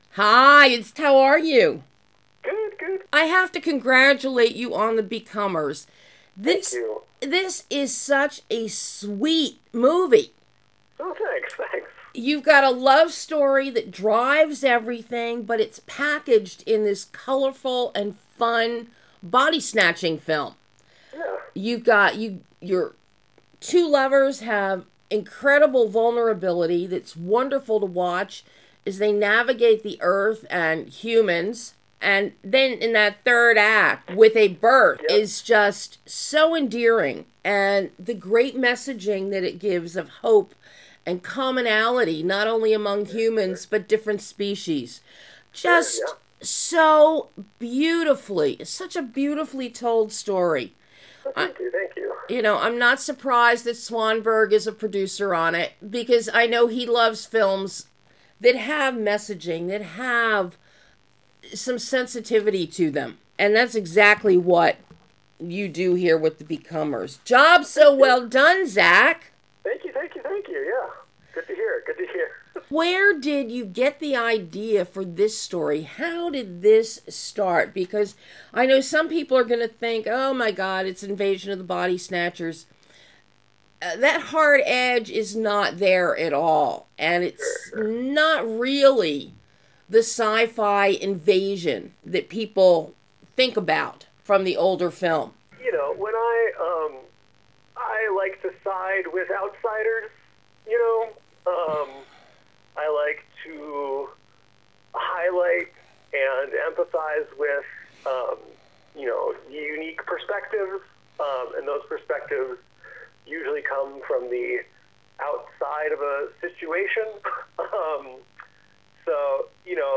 THE BECOMERS - Exclusive Interview